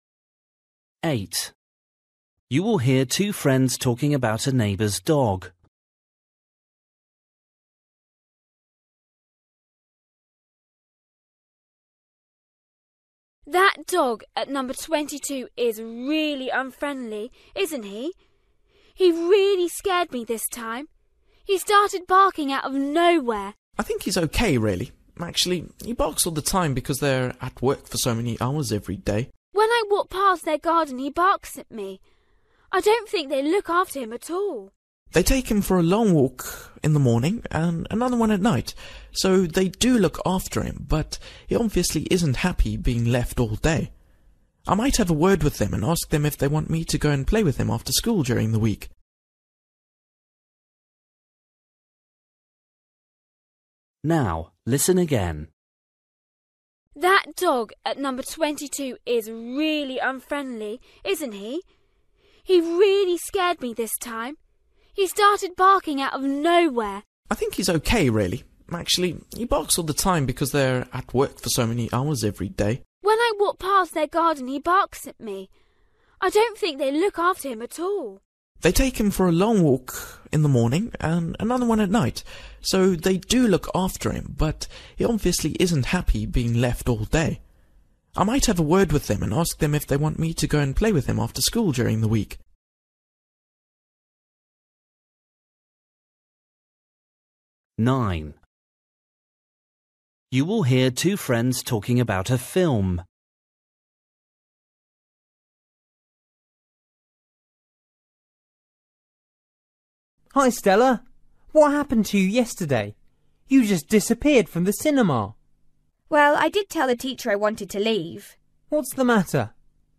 Listening: everyday short conversations
8   You will hear two friends talking about a neighbour’s dog. The boy thinks the dog
9   You will hear two friends talking about a film. Why did the girl leave the cinema early?